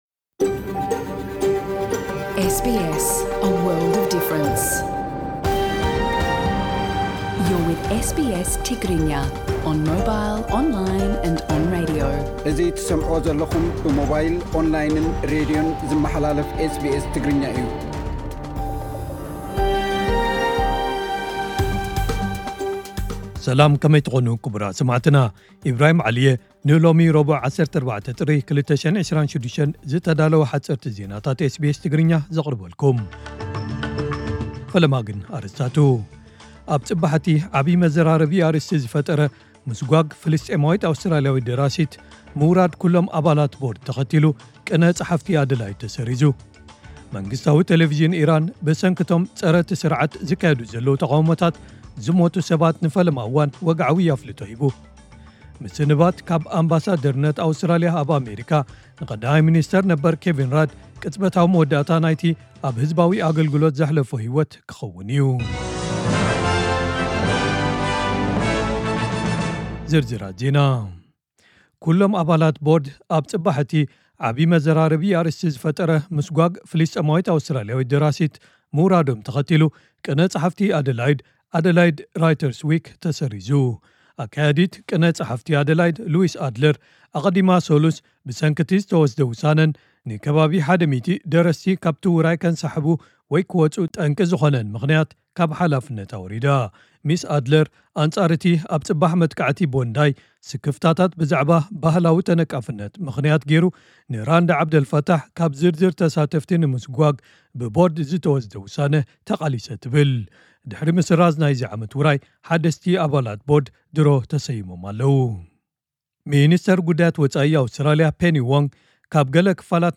ዕለታዊ ዜና ኤስቢኤስ ትግርኛ (14 ጥሪ 2026)